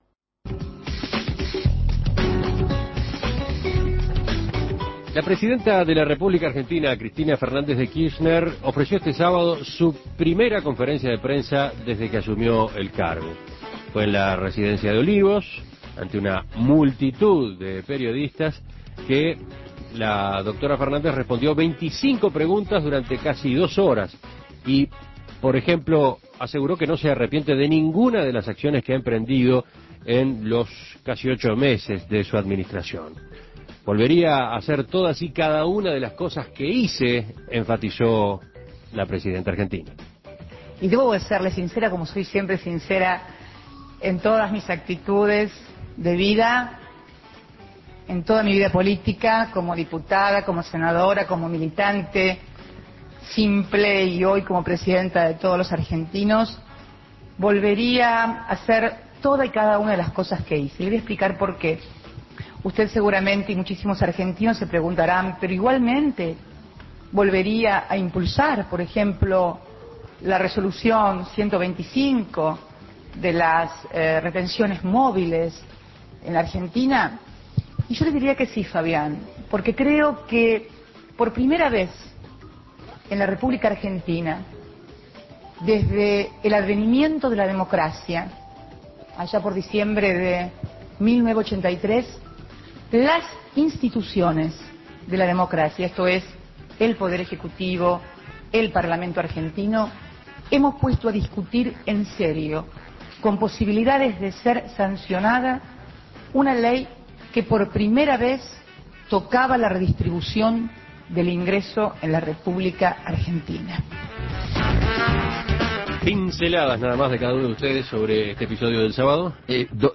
La presidenta argentina, Cristina Fernández, brindó su primera conferencia de prensa desde que asumió el cargo